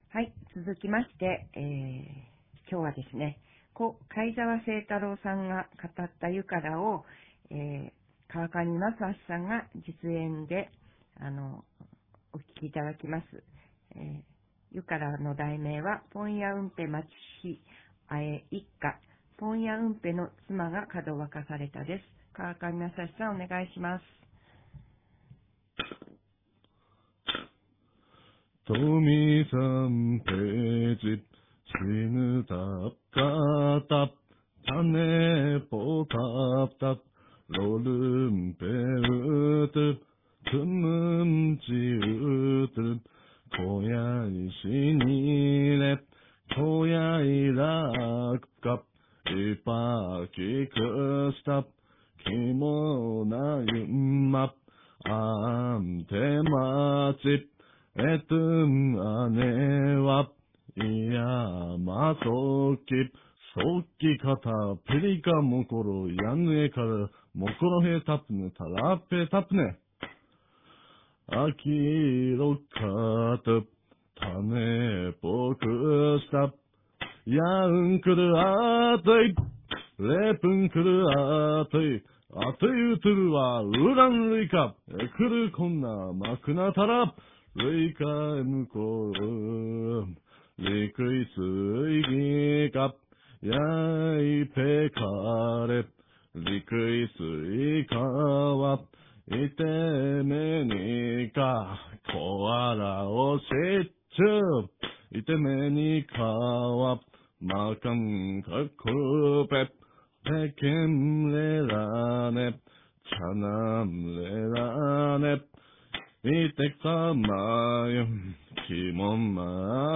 ■ユカの実演「ポンヤウンペ マチヒ アエイッカ」（ポンヤウンペの妻がかどわかされた）